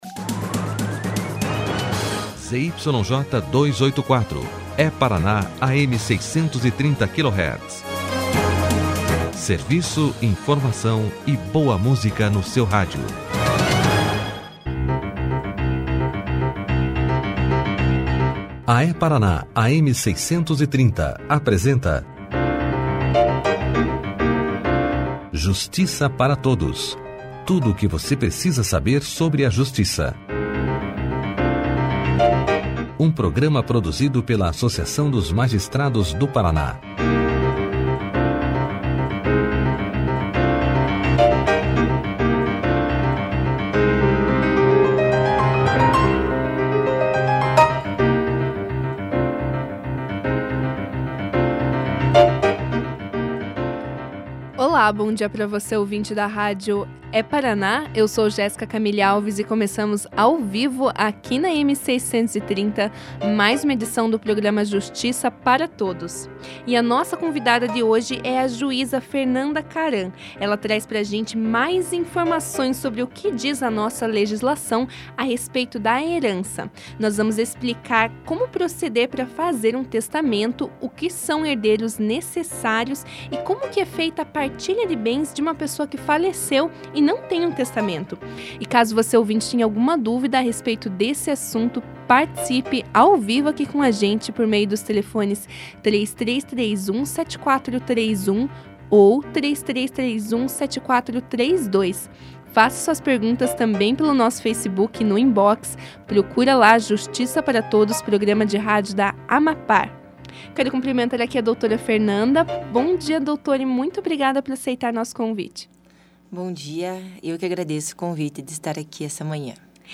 Confira na íntegra a entrevista com a juíza Fernanda Kamam